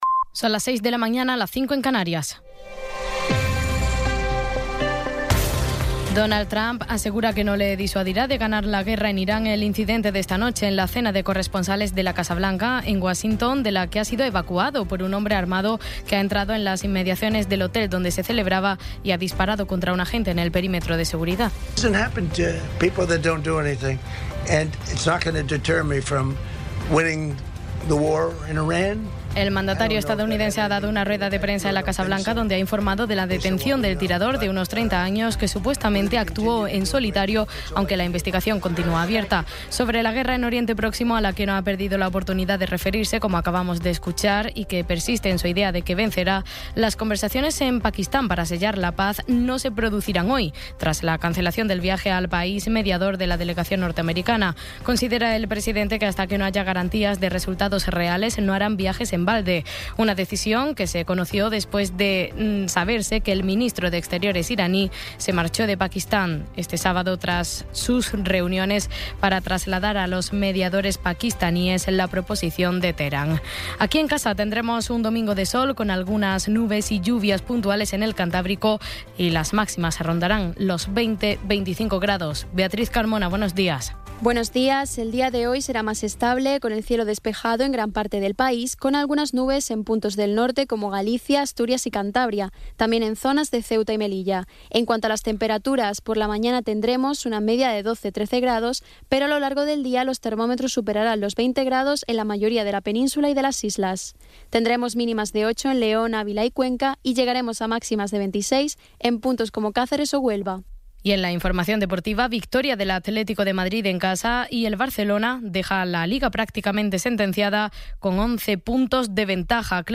Resumen informativo con las noticias más destacadas del 26 de abril de 2026 a las seis de la mañana.